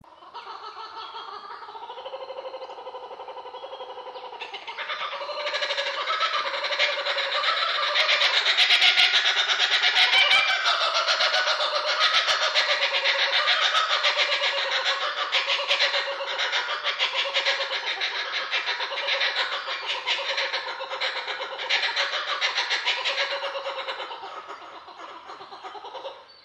Голоса птиц - Кукабарра (Kookaburra)
Отличного качества, без посторонних шумов.
157_kookaburra.mp3